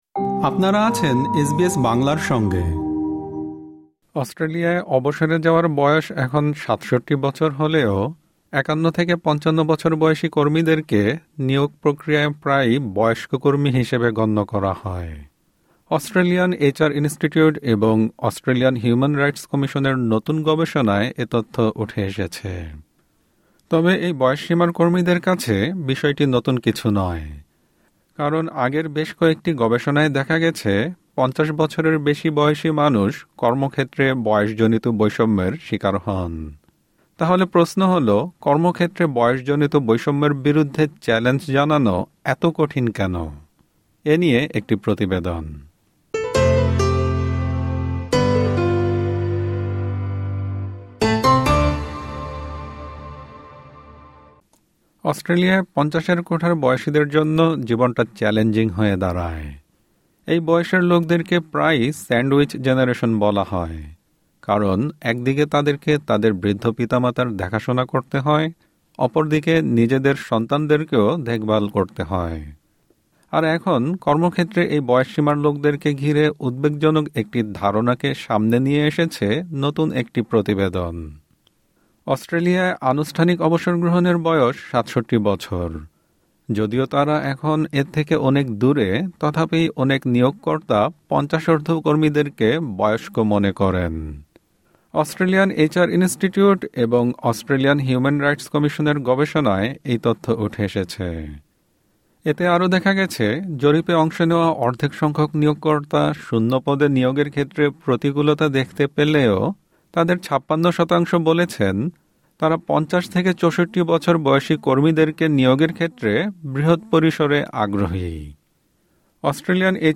সম্পূর্ণ প্রতিবেদনটি শুনতে উপরের অডিও-প্লেয়ারটিতে ক্লিক করুন।